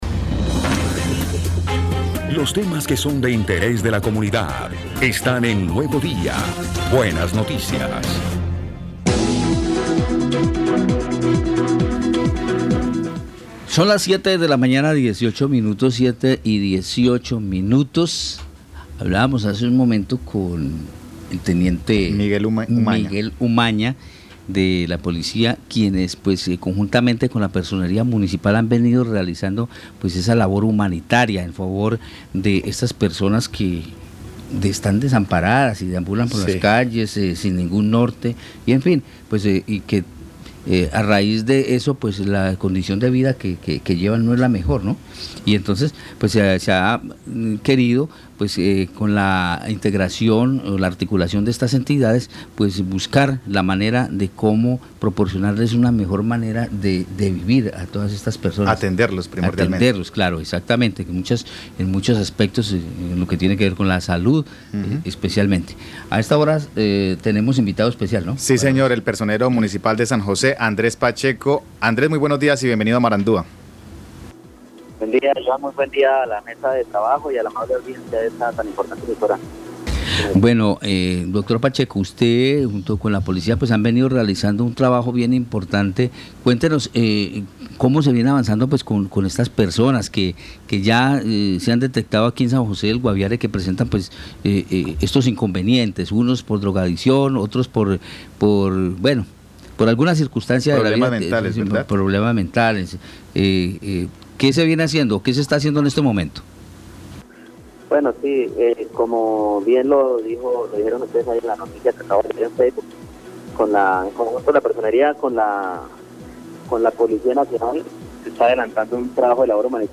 Andrés Pacheco, personero Municipal de San José del Guaviare.